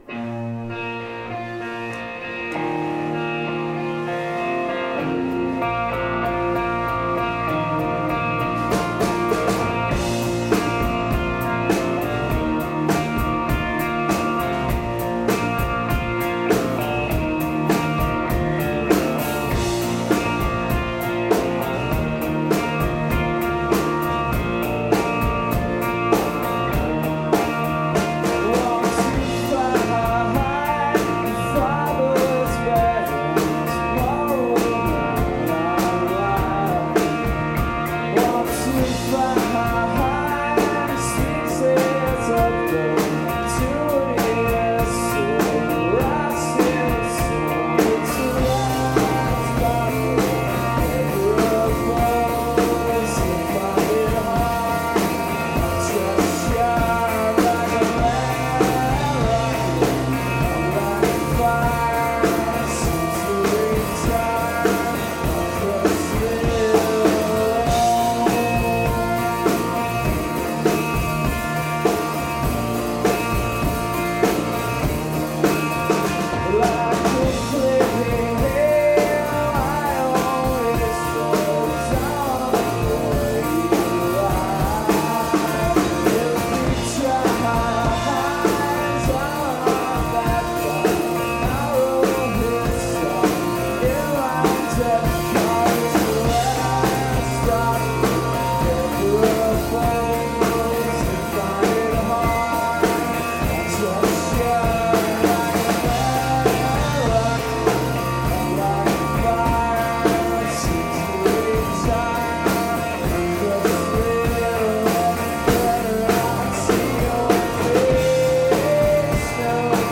northstar philadelphia July 18 2001